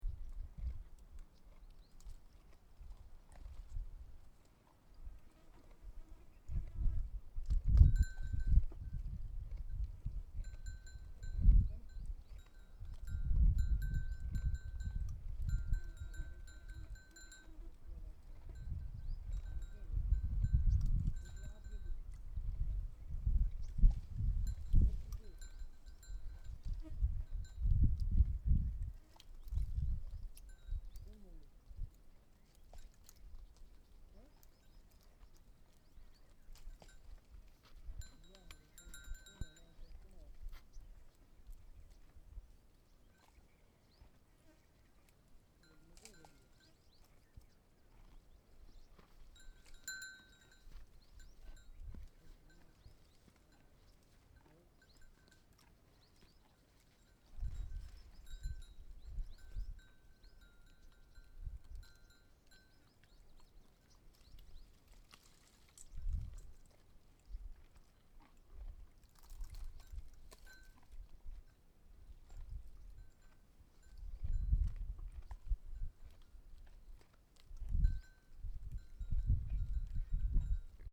desert1_camel.mp3